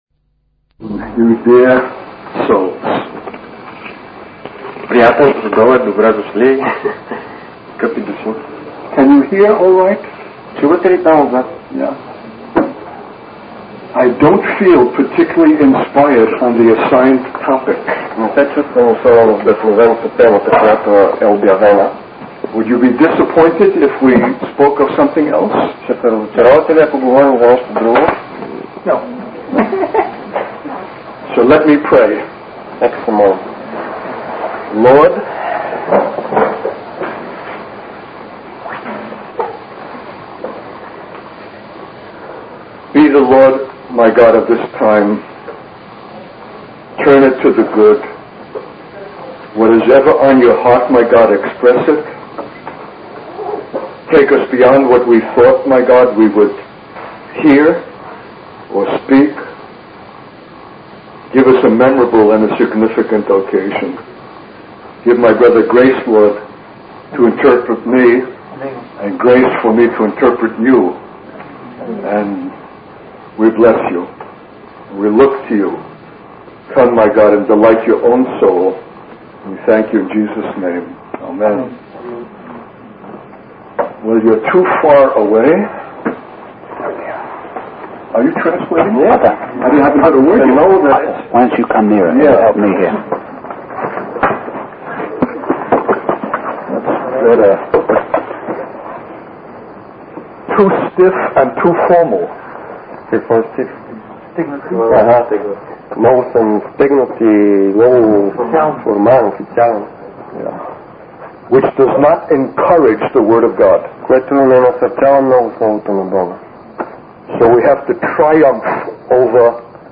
In this sermon, the preacher emphasizes the importance of living according to the will of God rather than seeking convenience or the approval of others.